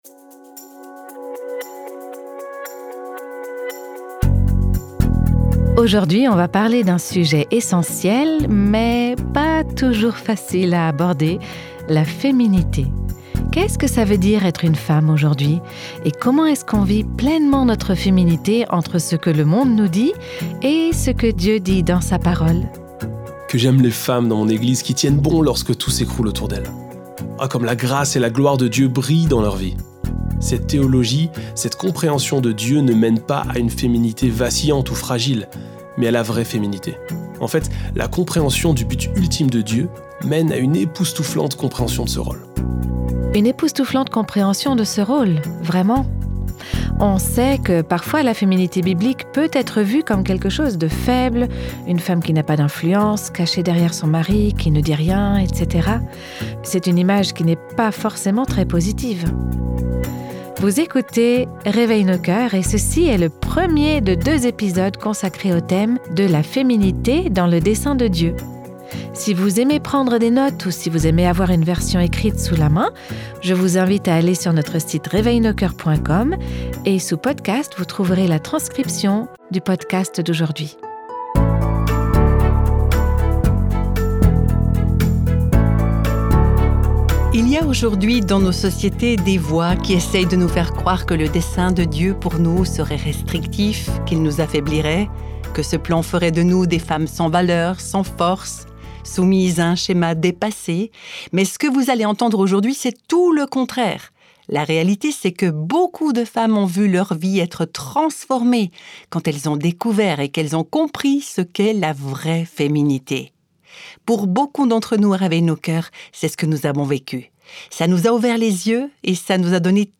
Lors d’une conférence, le pasteur John Piper a parlé des personnes qu’il considère comme étant les plus influentes au monde.